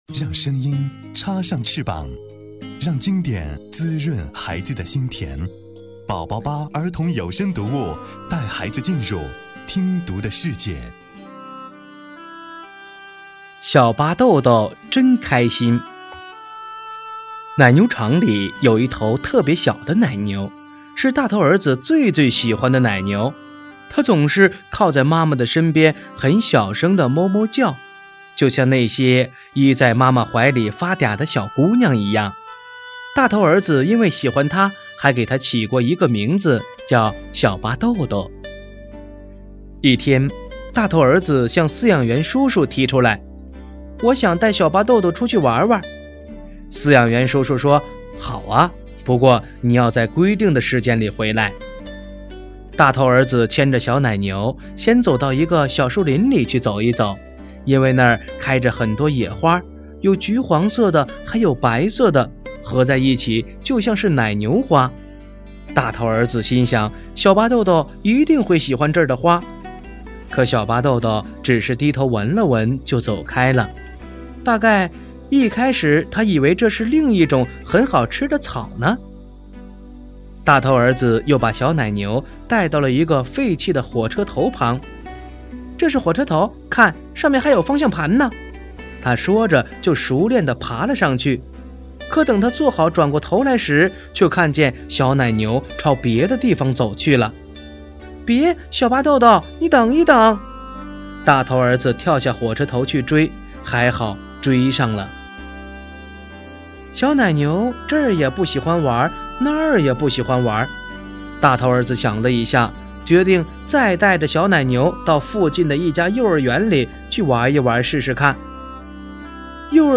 首页>mp3 > 儿童故事 > 05小巴豆豆真开心（大头儿子和奶牛妈妈们）